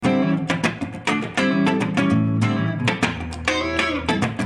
Funk guitars soundbank 1
Guitare loop - funk 30